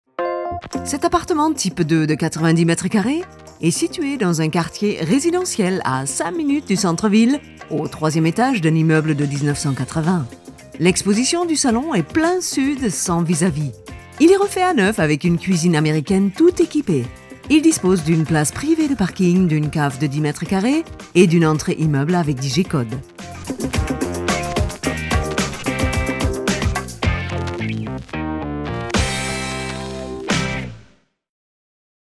Variable Stimme von sachlich bis charmant.
Kein Dialekt
Sprechprobe: Sonstiges (Muttersprache):
German Voice over artist with homestudio